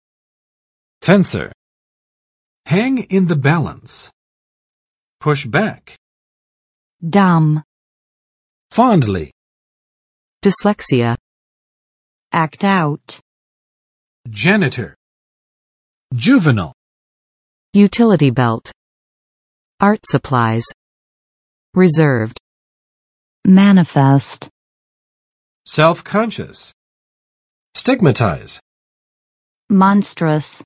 [ˋfɛnsɚ] n. 击剑运动员